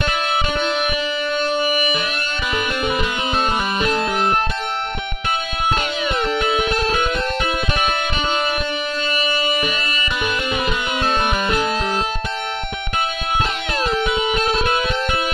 标签： 125 bpm Trap Loops Guitar Electric Loops 2.58 MB wav Key : Unknown
声道立体声